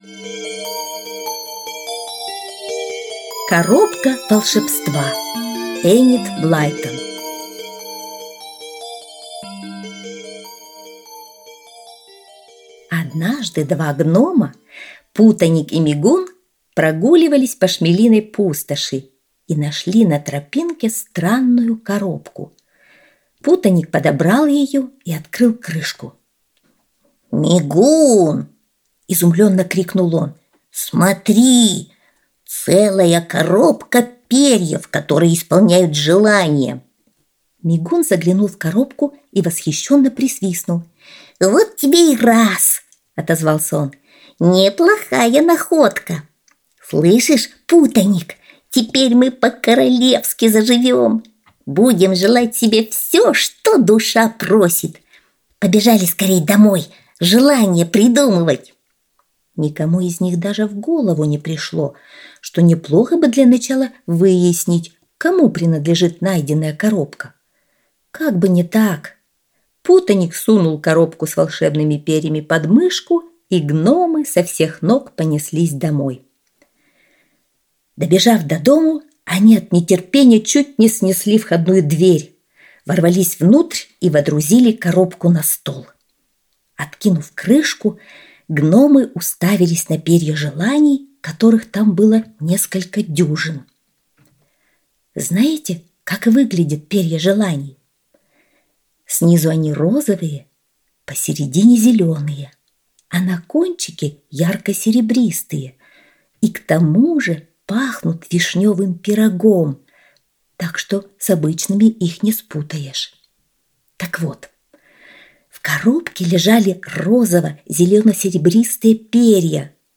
Коробка волшебства - аудиосказка Энид Блайтон - слушать